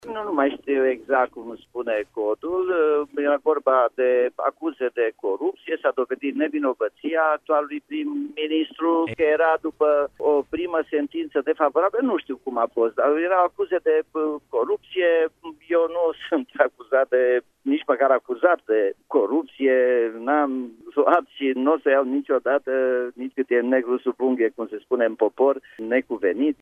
Într-o intervenție în direct la Radio Timişoara, edilul a declarat că situaţia sa nu este asemănătoare cu cea a liderului PNL, Ludovic Orban, care a renunţat la candidatură după ce împotriva sa a fost declanşată urmărirea penală.